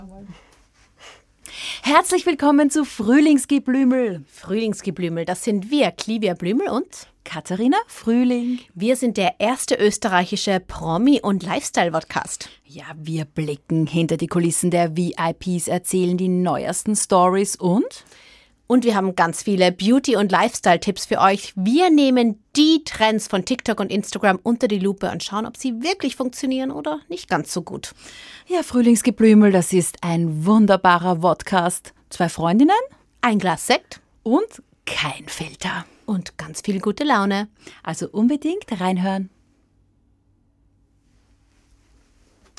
FrühlingsgeBlümel Zwei Freundinnen, ein Gläschen Sekt, Null Filter!